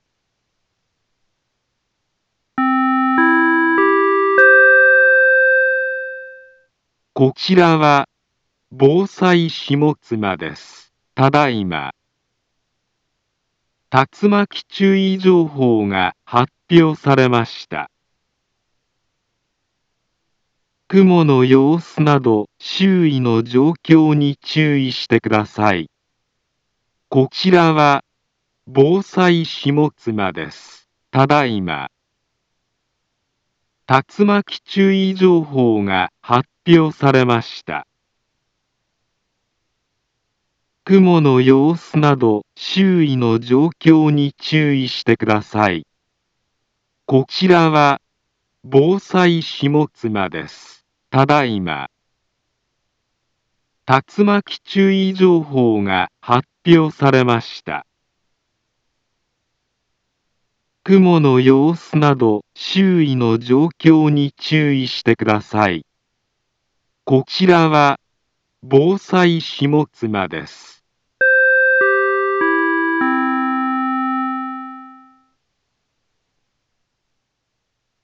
Back Home Ｊアラート情報 音声放送 再生 災害情報 カテゴリ：J-ALERT 登録日時：2023-07-11 19:59:49 インフォメーション：茨城県北部、南部は、竜巻などの激しい突風が発生しやすい気象状況になっています。